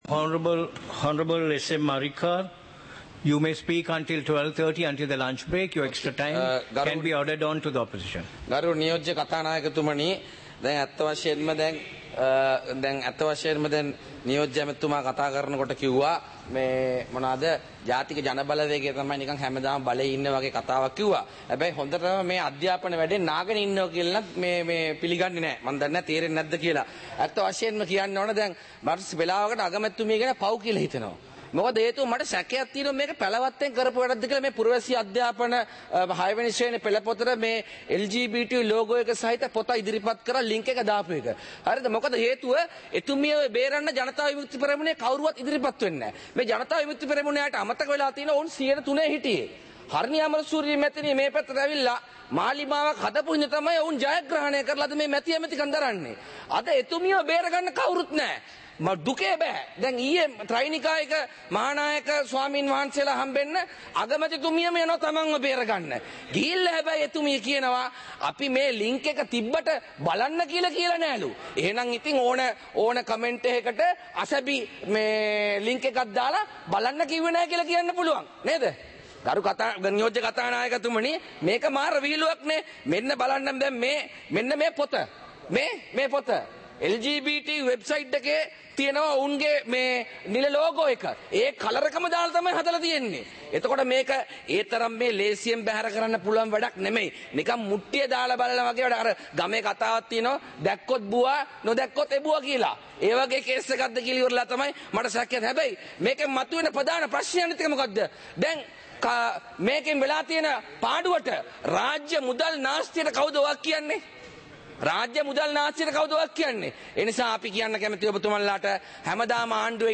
இலங்கை பாராளுமன்றம் - சபை நடவடிக்கைமுறை (2026-01-09)